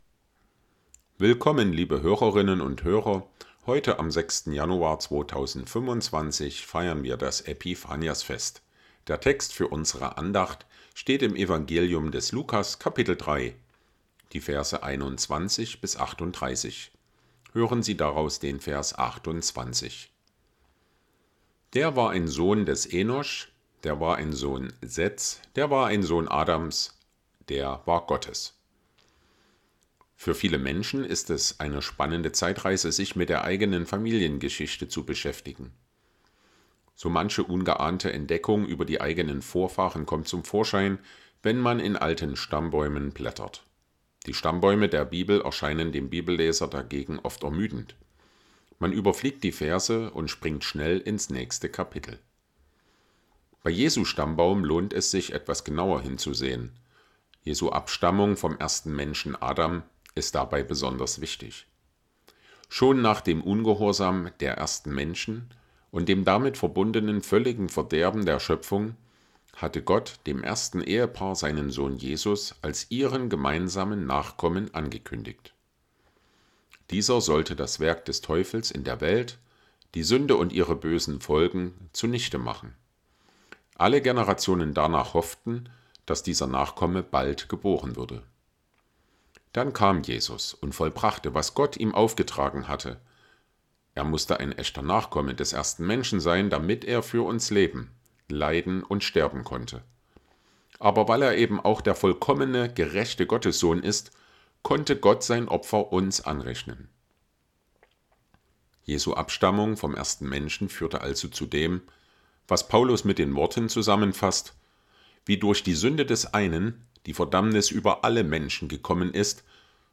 Andacht vom 06.01.2025
Tägliche Andachten aus dem Andachtsheft der Ev.-Luth. Freikirche